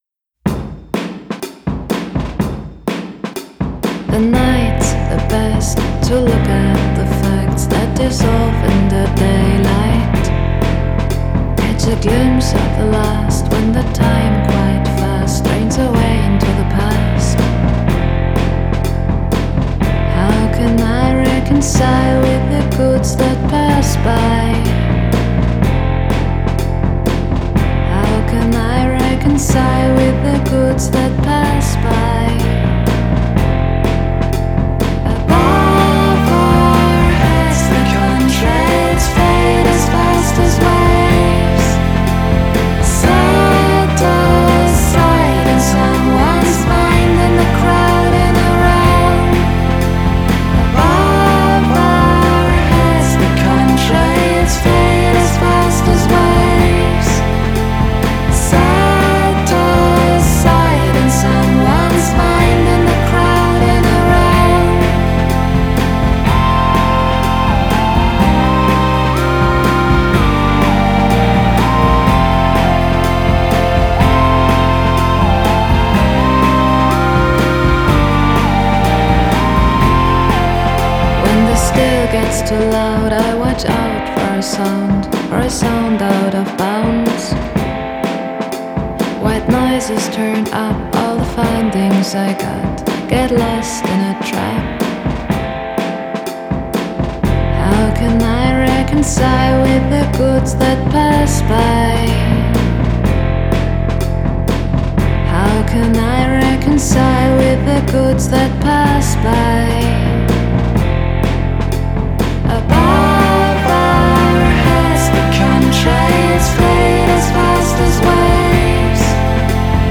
Genre: Dream Pop, Indie Folk, Singer-Songwriter